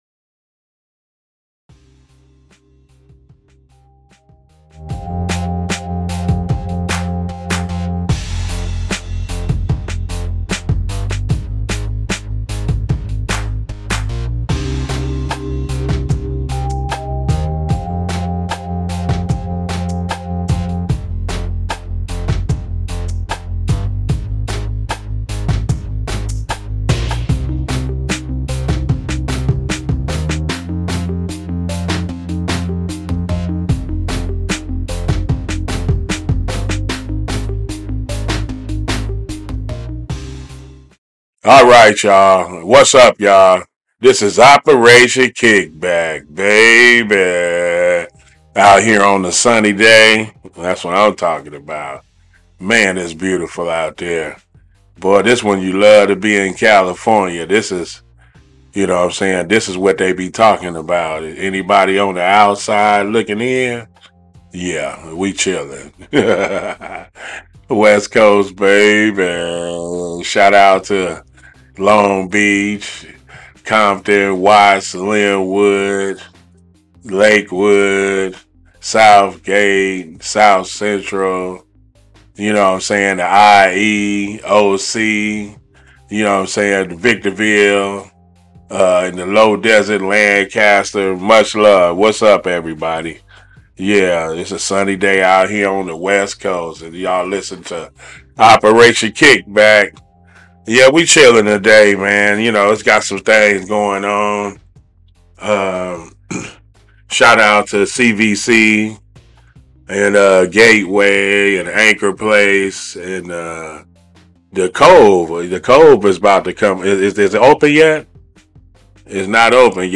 This episode of Operation KickBack aired live on CityHeART Radio Tuesday May 7 at 2pm.